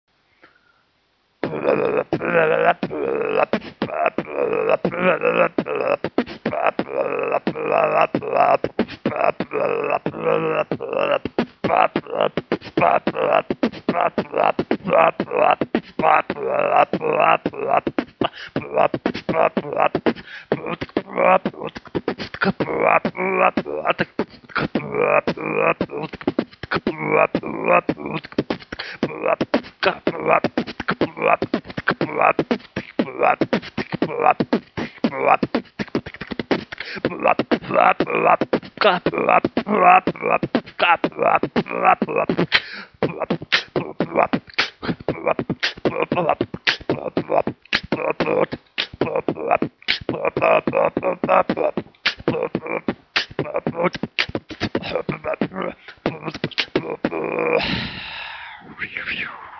и еще вроде как нечто технофристайла))))